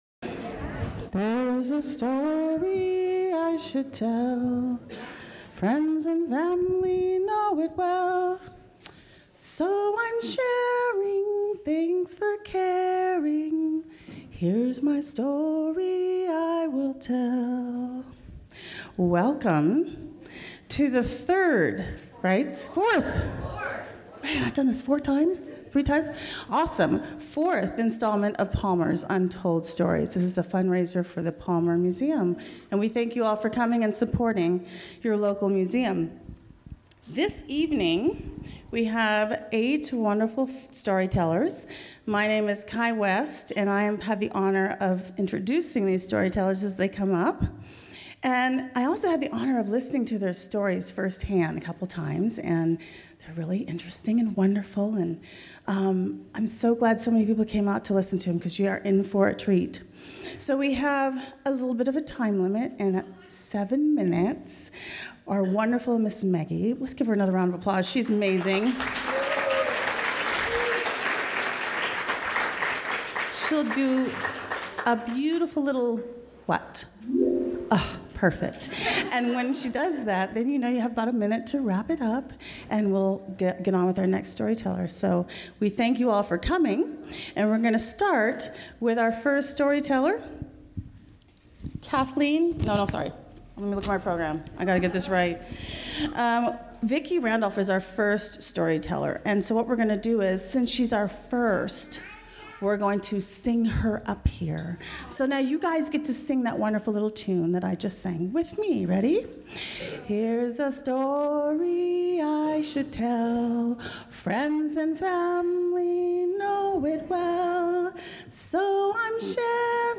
The Palmer Museum of history and Art sponsored another Untold Stories event at the Palmer Depot on Sunday, April 23, 2017. Enjoy the recording as seven local story tellers remind us of what “breakup” can really mean.